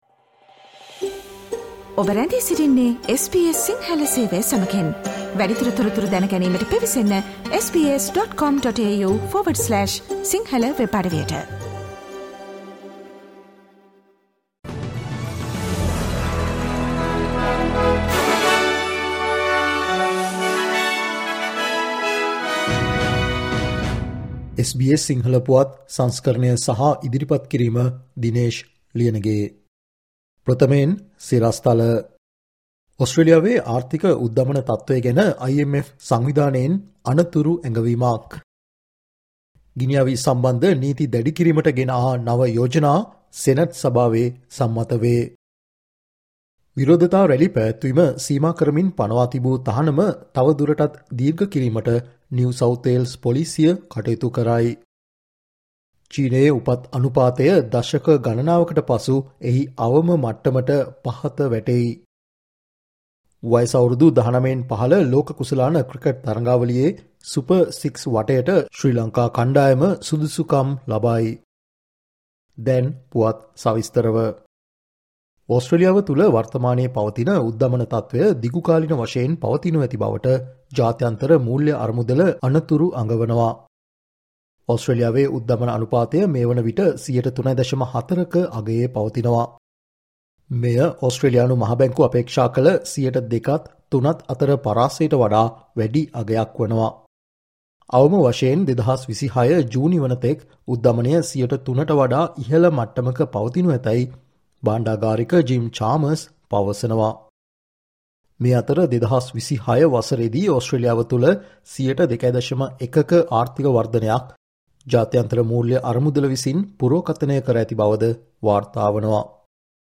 ඕස්ට්‍රේලියාවේ පුවත් සිංහලෙන් දැන ගන්න, ජනවාරි මස 21 වන දා SBS සිංහල පුවත් වලට සවන් දෙන්න.